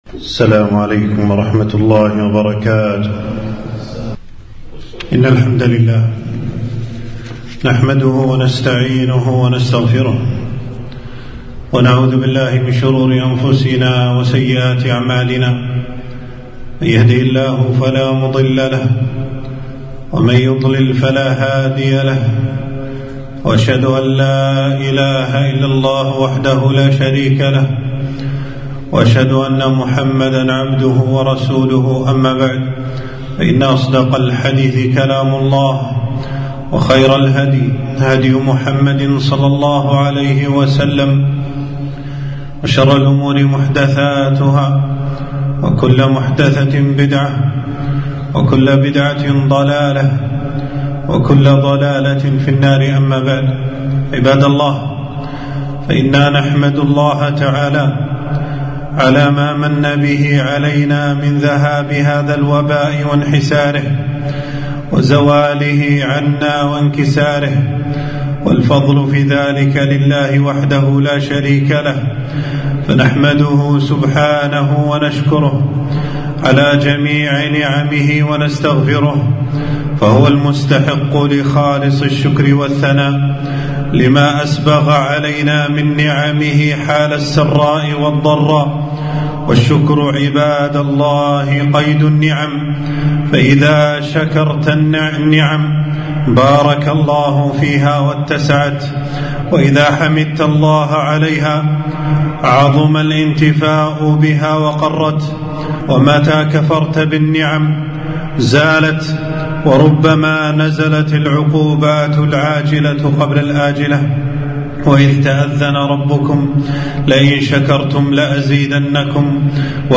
خطبة - شكر الله على زوال البلاء